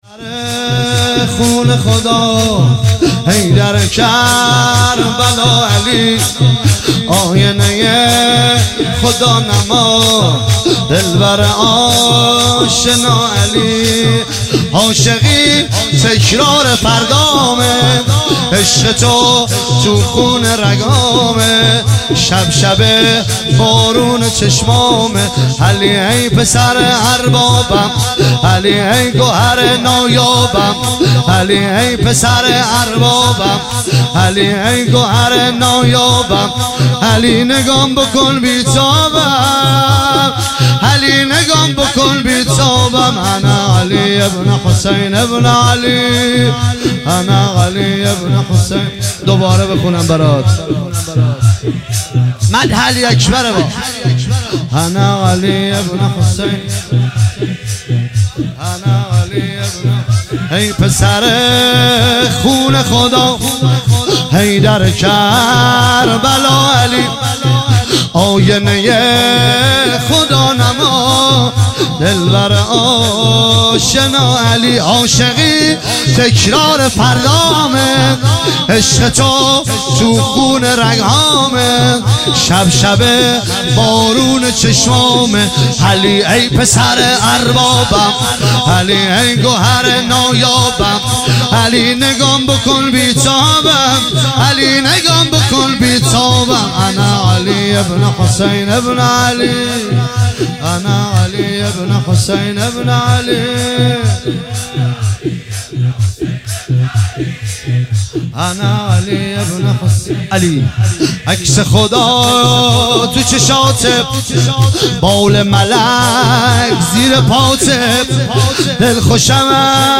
مراسم شب هشتم محرم ۱۳۹۷
شور